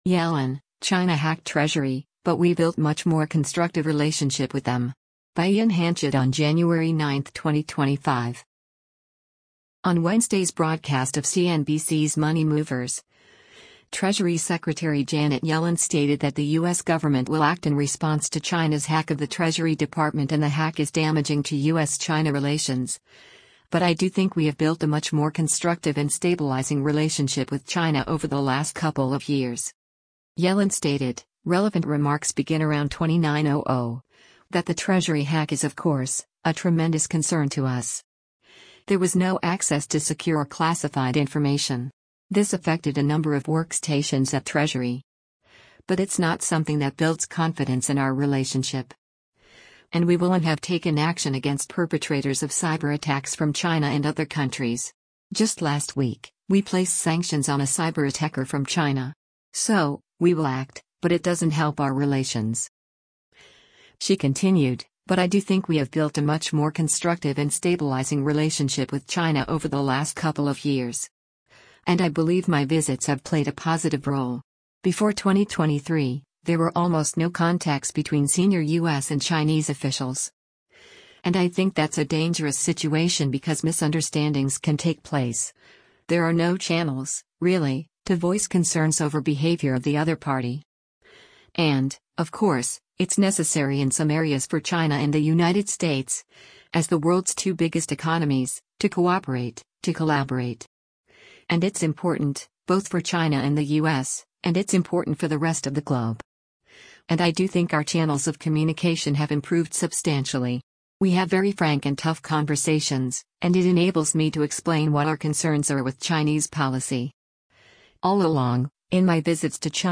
On Wednesday’s broadcast of CNBC’s “Money Movers,” Treasury Secretary Janet Yellen stated that the U.S. government will act in response to China’s hack of the Treasury Department and the hack is damaging to U.S.-China relations, “But I do think we have built a much more constructive and stabilizing relationship with China over the last couple of years.”